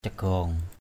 /ca-ɡ͡ɣɔ:ŋ/ (đg.) ngoạm, tha đi = emporter avec la gueule (le bec….) asau cagaong sa klaih ralaow as~@ c_g” s% =k*H r_l<| chó tha đi một miếng thịt = le chien...
cagaong.mp3